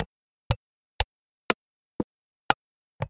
things » soap dispenser
描述：I press on the soap dispenser's head again and again. It makes a springsquishing sound. Recorded with a Zoom H2.
标签： publicdomain press pressing mechanism spring squish squishing
声道立体声